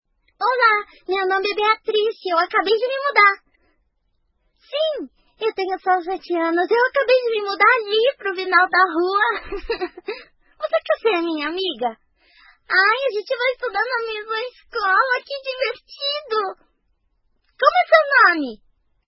Voces infantiles de Brasil